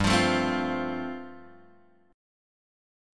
G9sus4 chord {3 x 3 2 1 1} chord